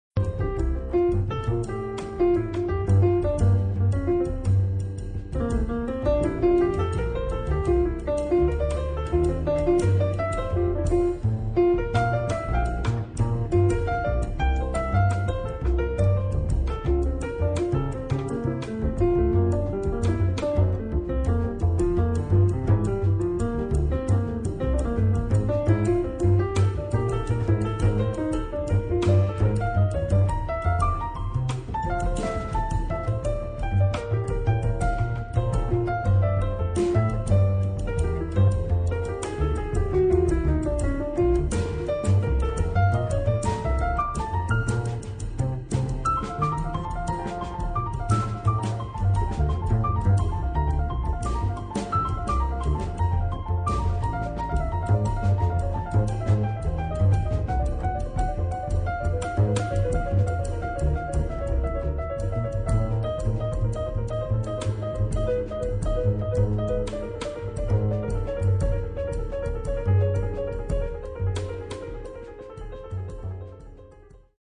è un piano trio dei più classici